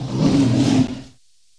PixelPerfectionCE/assets/minecraft/sounds/mob/polarbear/hurt2.ogg at mc116